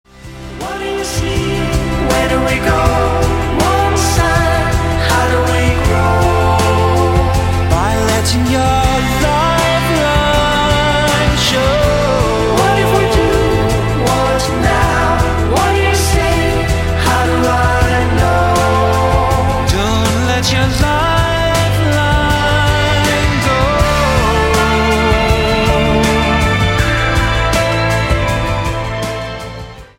Танцевальные рингтоны / Романтические рингтоны